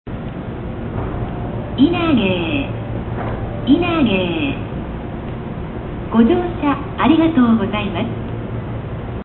駅名連呼「稲毛駅」
駅名連呼です。
「○○、です」ではなく、「○○、」に変わり少し自然になりました。